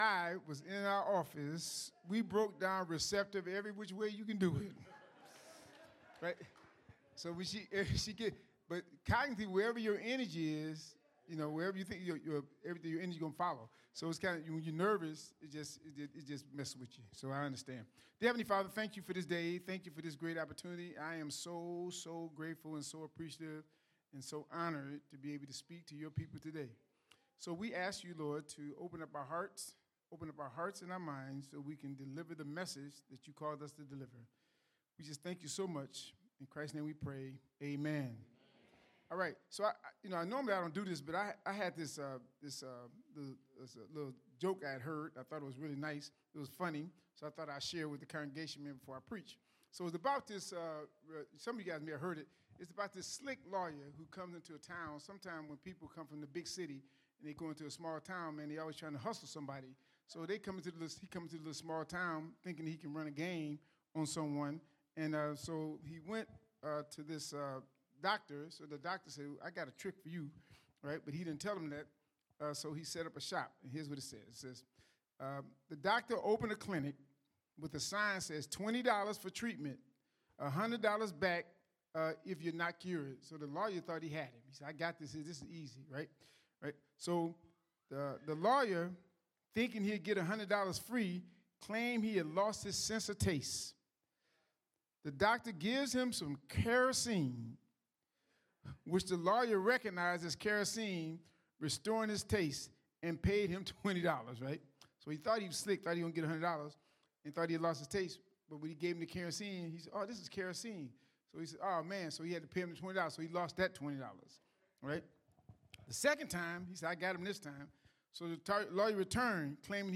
Sunday Morning Service 1-25-26 (From The Bottom up)